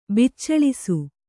♪ biccaḷisu